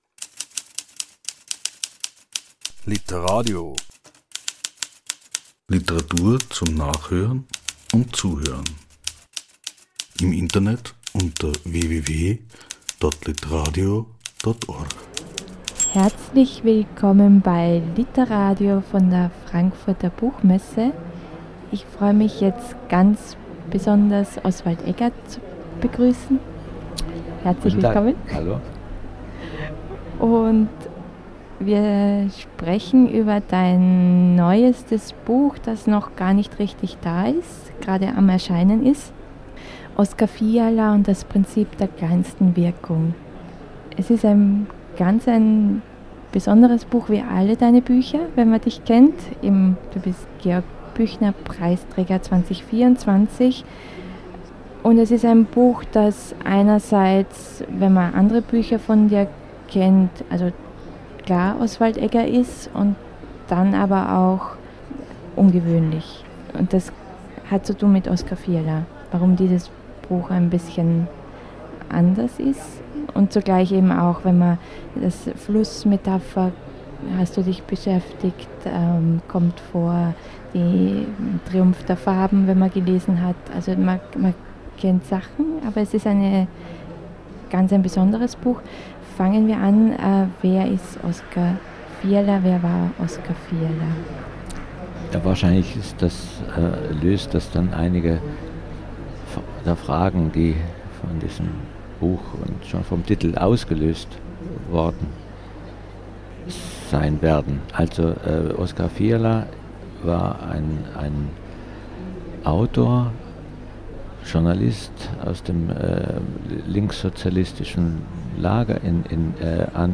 literadio-Gespräch